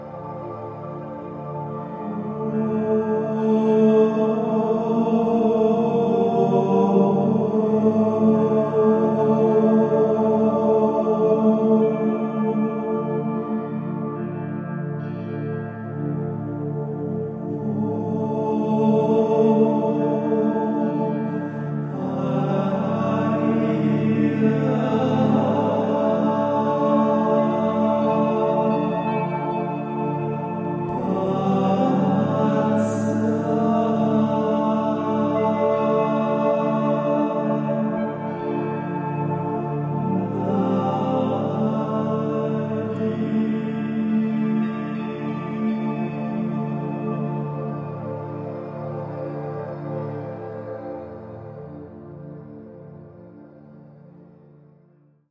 A reverent musical tribute to the Ascended Masters
a unique blend of Oriental & Western musical traditions
Mastered with 444 Hz Solfeggio Frequencies.